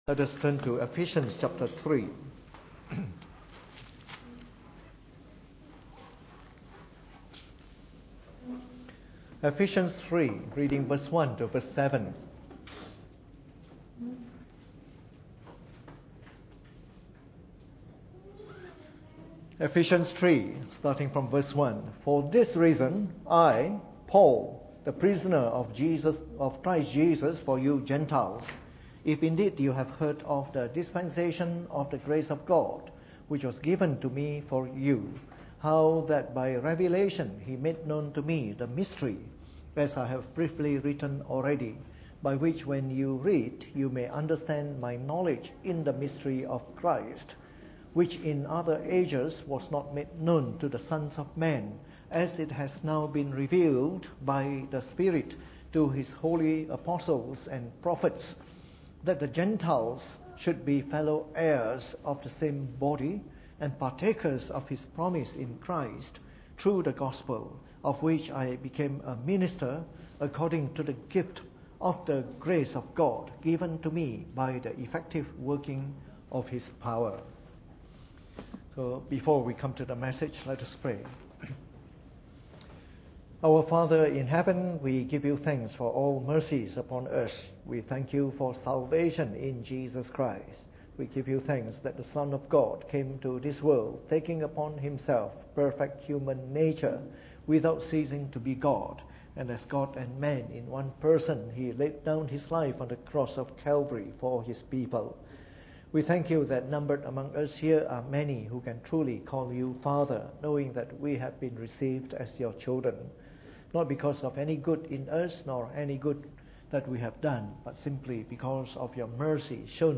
Preached on the 16th of August 2017 during the Bible Study, from our series on Church Planting Today.